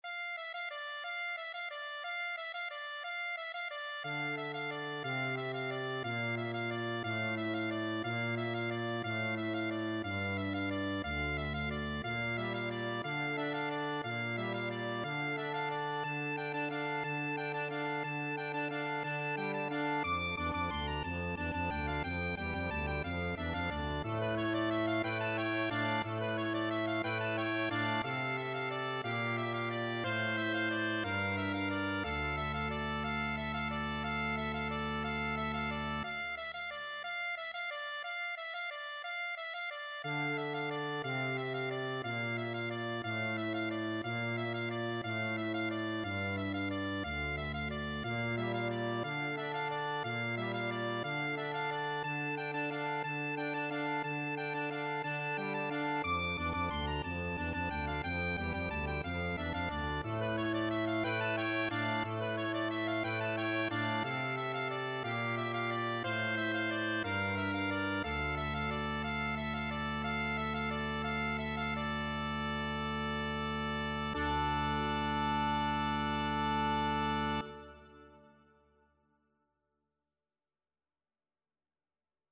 Traditional Trad. Carol of the bells Accordion version
D minor (Sounding Pitch) (View more D minor Music for Accordion )
3/4 (View more 3/4 Music)
Fast (=c.180)
Accordion  (View more Intermediate Accordion Music)
Traditional (View more Traditional Accordion Music)